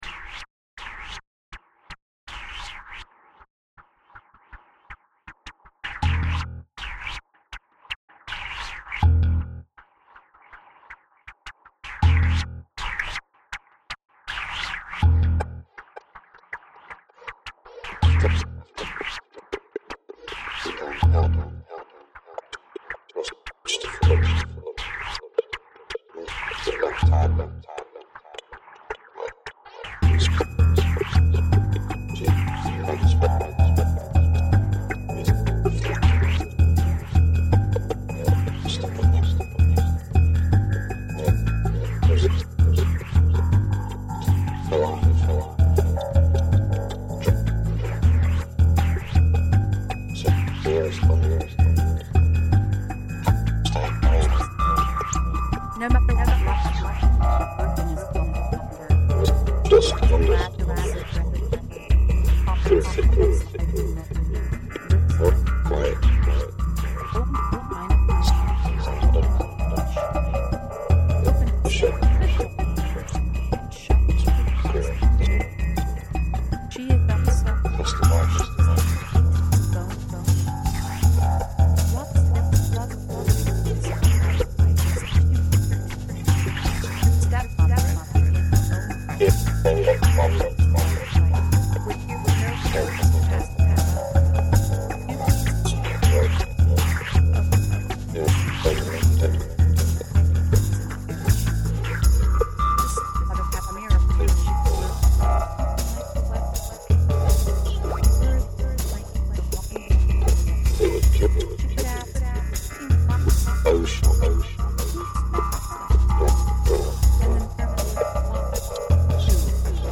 look, my mom visited for two weeks so i could either go into the studio and be all antisocial with my mom who lives thousands of miles away or i could mess around with some electronica in the living room. i opted for the latter and as a result had a rich visit with my mother and avoided being smacked upside the head (for ignoring her).
in any case, this is about 6:18 of tunage. i like the voices that you can barely understand. they’re some friends of mine reading various things and stuff. i really love working with the human voice and obscuring it. i can also say that i am getting over my fear of drum loops. i think i “get it” now. or maybe i’ve just given in and decided that i’m not a very creative percussionist and loops make my life easier. i think i like the “getting over the fear” option.
garage band tomfoolery, music, original music